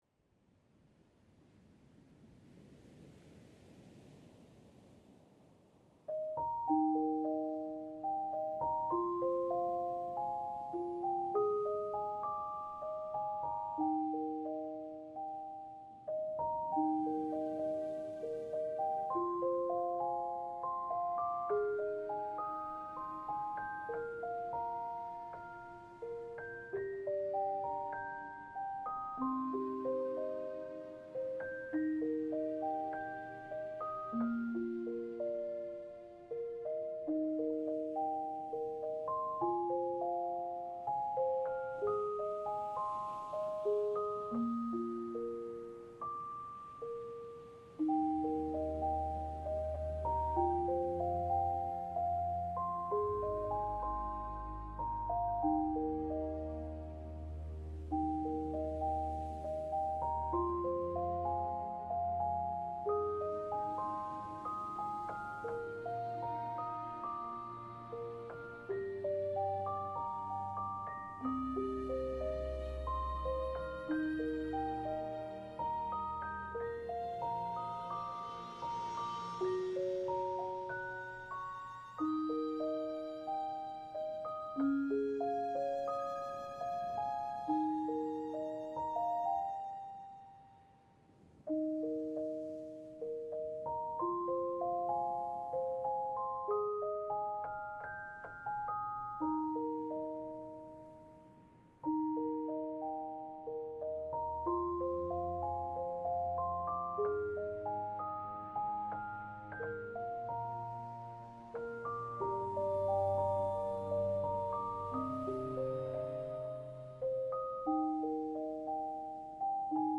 1 Stunde Spieluhr-Schlaf